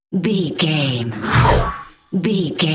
Sci fi horror whoosh fast
Sound Effects
Atonal
Fast
tension
ominous
eerie